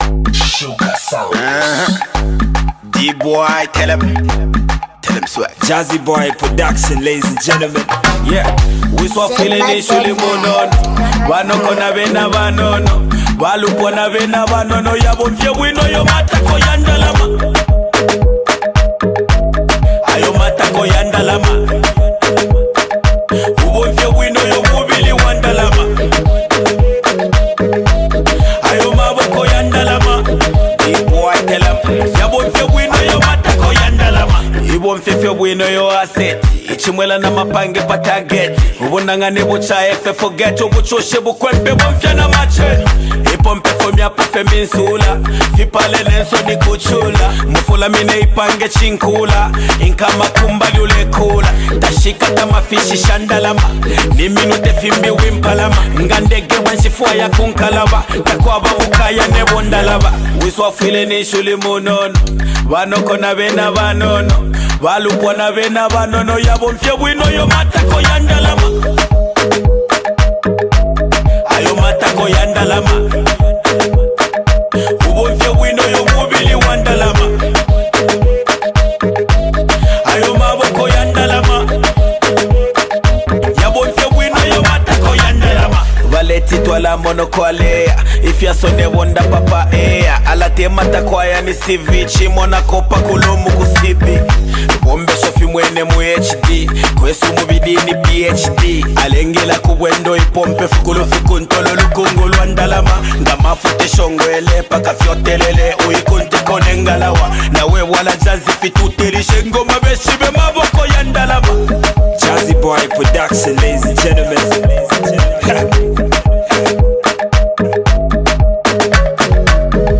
Genre: Zambian Music